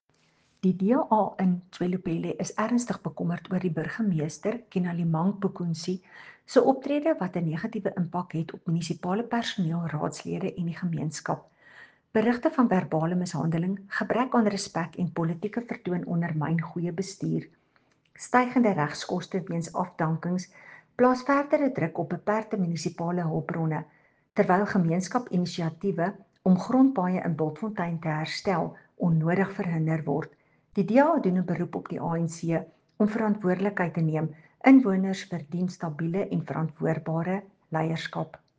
Afrikaans soundbite by Cllr Estelle Pretorius and